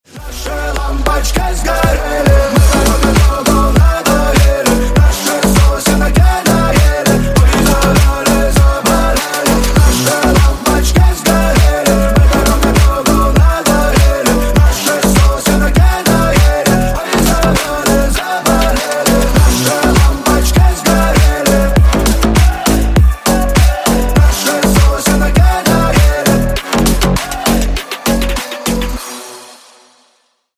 # Клубные Рингтоны
# Рингтоны Ремиксы » # Танцевальные Рингтоны